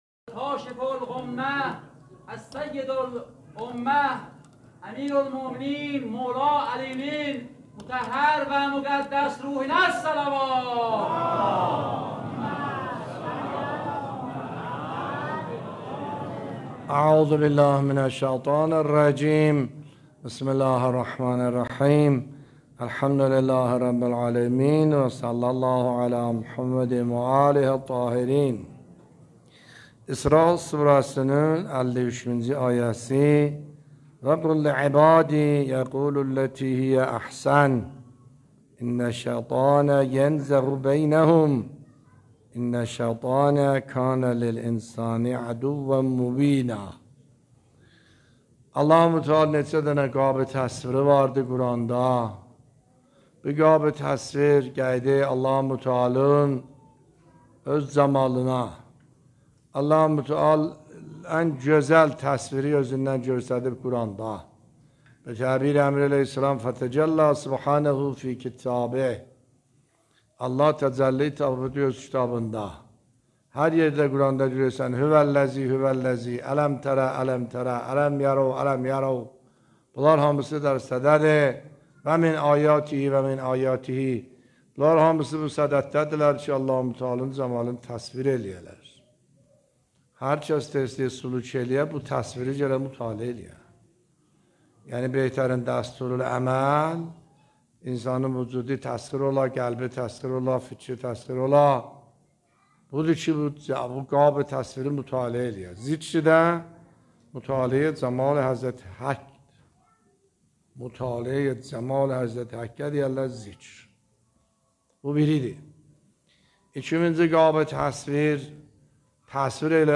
نکات برگزیده تفسیری آیه 53 سوره مبارکه اسراء در بیان دلنشین آیت الله سید حسن عاملی در مسجد مرحوم میرزا علی اکبر در اولین شب رمضان المبارک 1402 به مدت 18 دقیقه